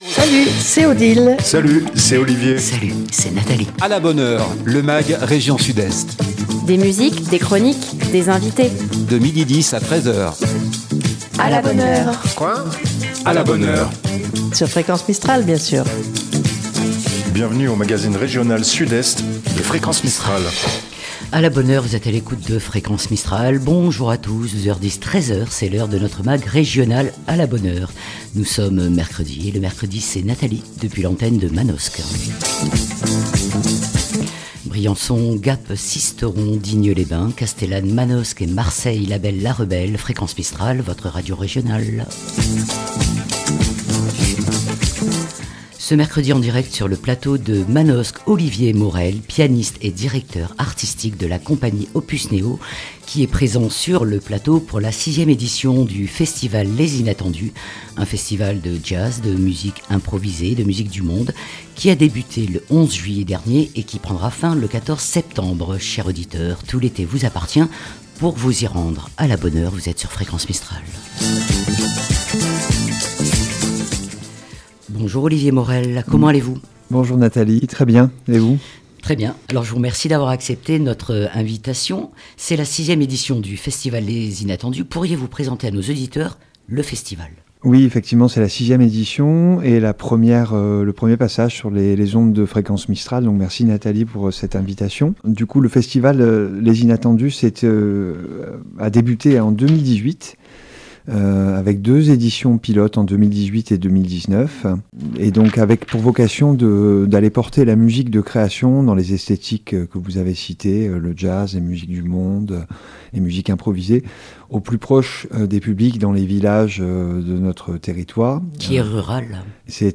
Aujourd'hui, dans le magasine régional " A la bonne heure " 12h10 - 13 h 00, nous recevons